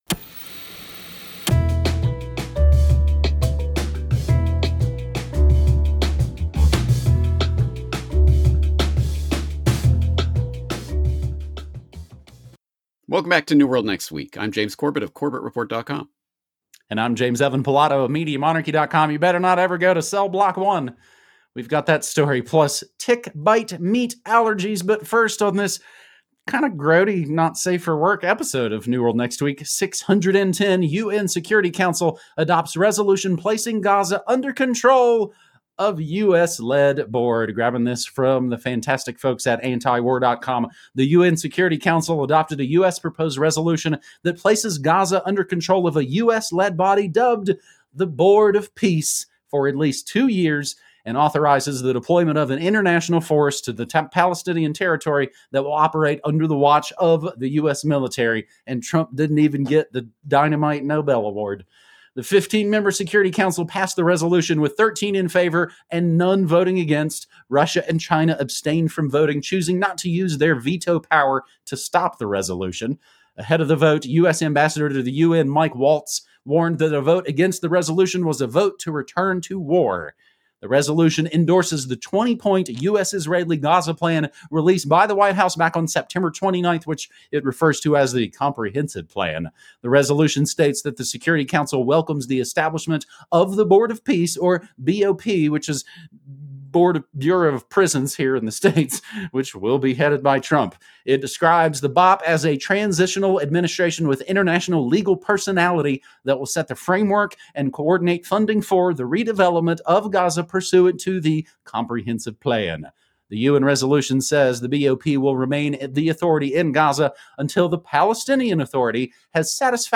Issues covered include 9/11 and false flag terror, the Big Brother police state, the global warming hoax and how central banks control the political process. Guests include politicians, scientists, activists and newsmakers from around the world.